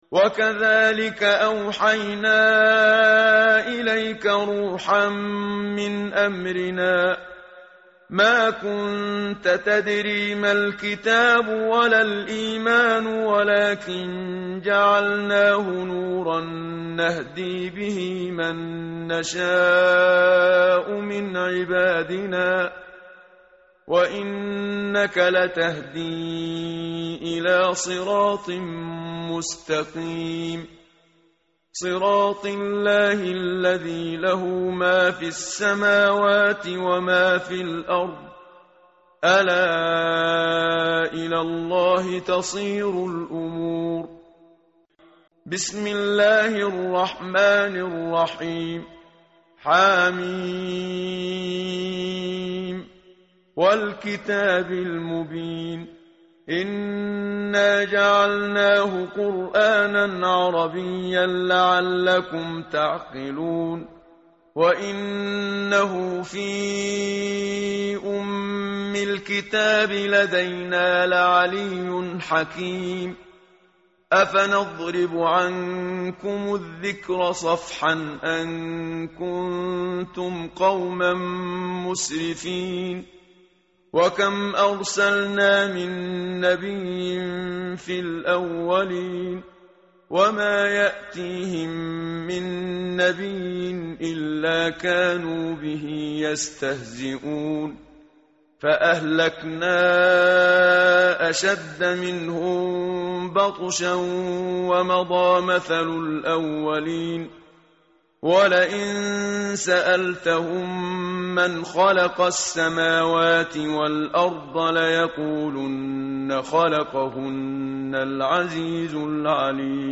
tartil_menshavi_page_489.mp3